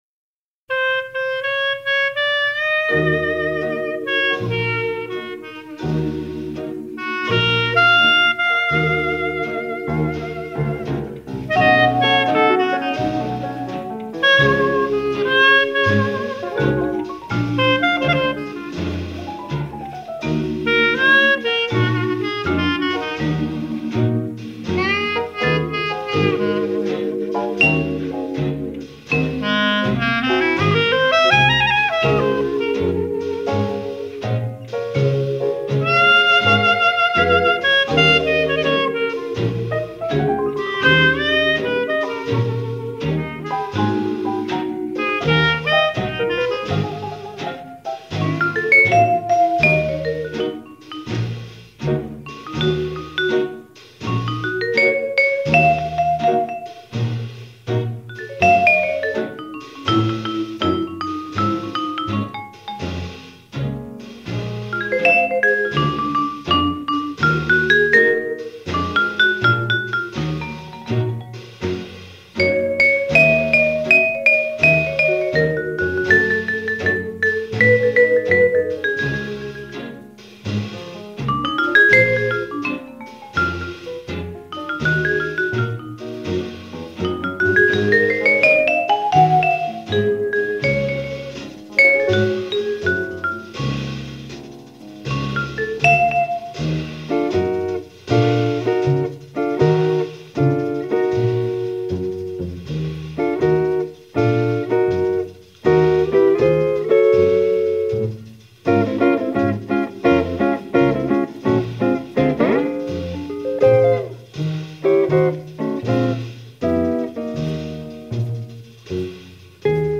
clarinet
piano
vibraphone
guitar
bass
drums.